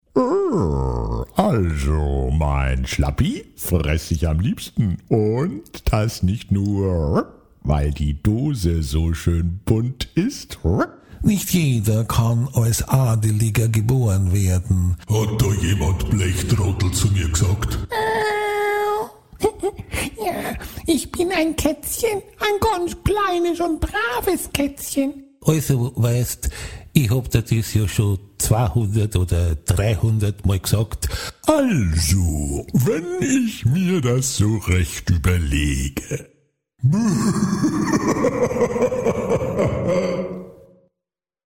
Werbung MercedesBenz DFB